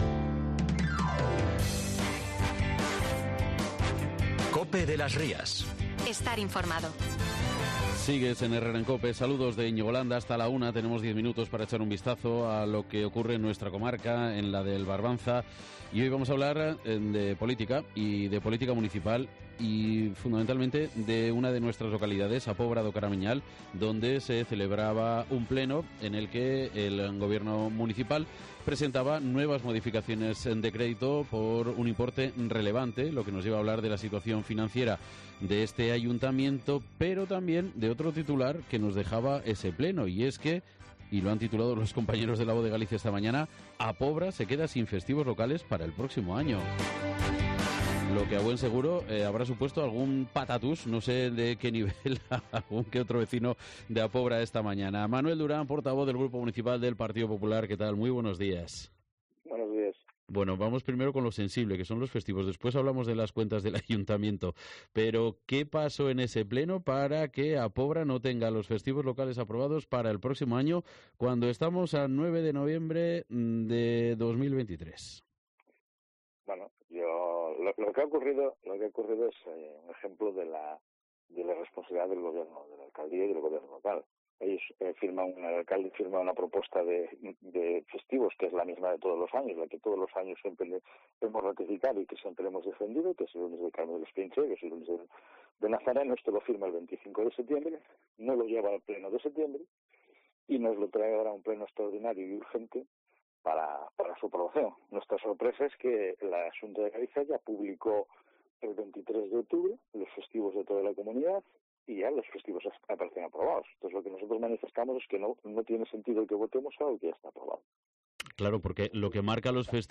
Hablamos con el portavoz del grupo municipal del PP en A Pobra do Caramiñal, Manuel Durán, tras concoerse la irregularidad administrativa que deja ahora a esta localidad del Barbanza sin sus festivos locales aprobados para el próximo año por el pleno de la corporación, aunque sí publicados en el DOG del pasado 23 de octubre.